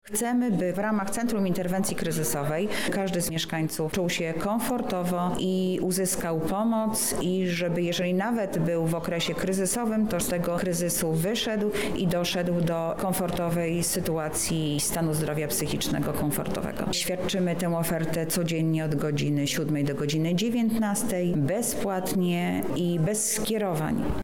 Anna Augustyniak  – mówi Anna Augustyniak, Zastępca Prezydenta Miasta Lublin ds. Społecznych.